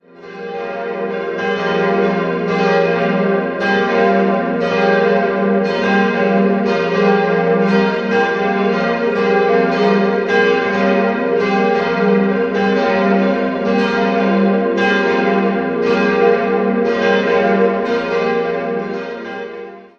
Die Kirche wurde 1966 erweitert und umgebaut und dabei die Wandmalereien freigelegt. 4-stimmiges Geläute: f'-g'-b'-c'' Die drei größeren Glocken wurden im Jahr 1966 von der Gießerei Bachert gegossen, die kleine - ebenfalls von Bachert - kam im Jahr 2000 hinzu.